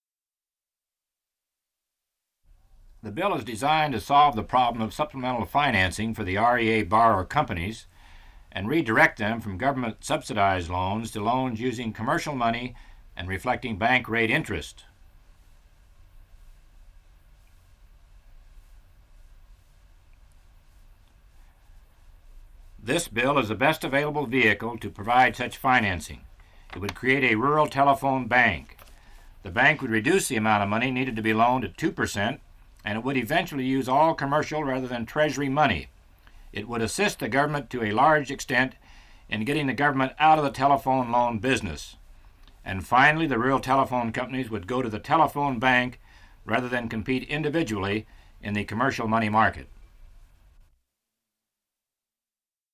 Part of Bob Dole Radio Spot on a US House of Representatives Bill